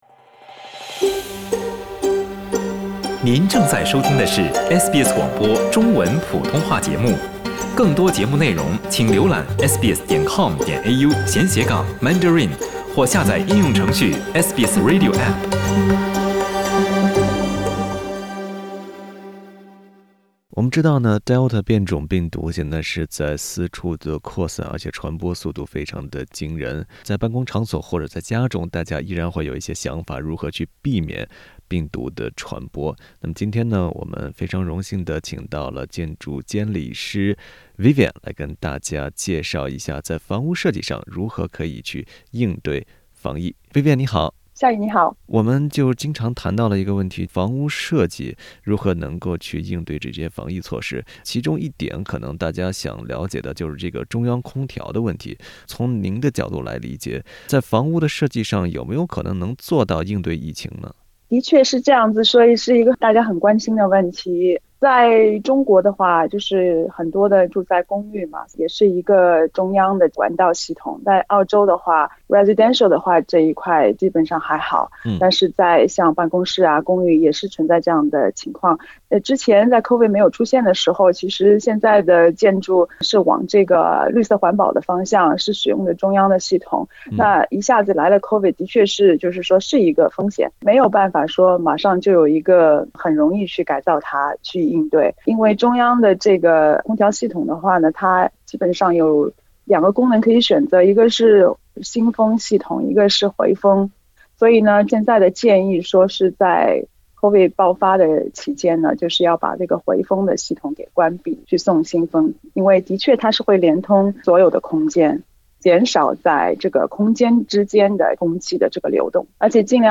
(点击图片音频，收听采访）